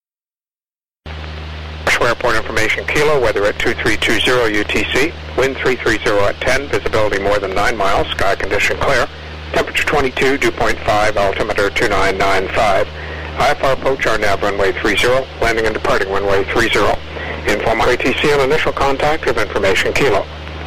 Oshawa Airport weather at 2320 utc